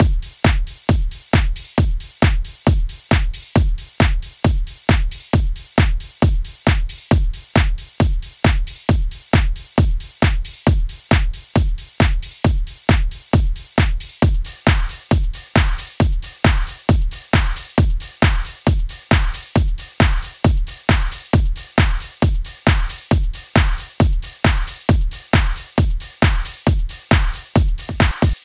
TI CK7 135 Drums Full.wav